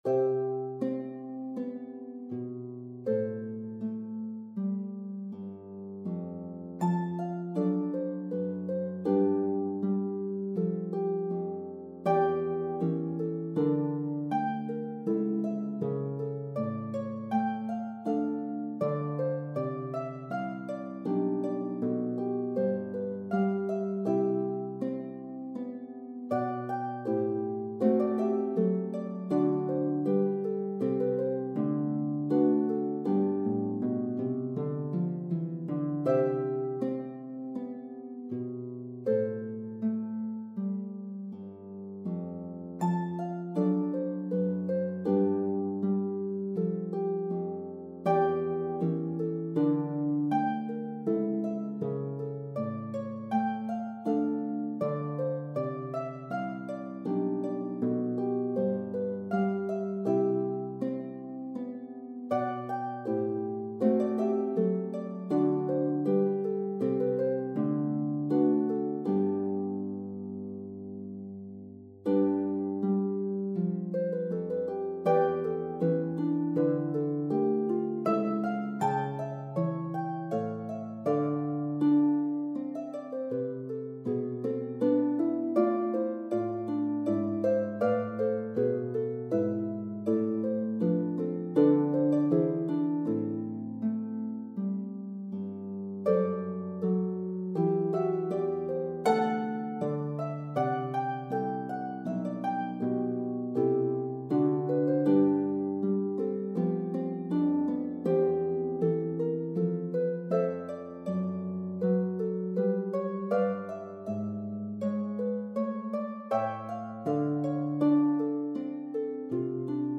Harp version
4/4 (View more 4/4 Music)
C major (Sounding Pitch) (View more C major Music for Harp )
Andante
Harp  (View more Intermediate Harp Music)
Classical (View more Classical Harp Music)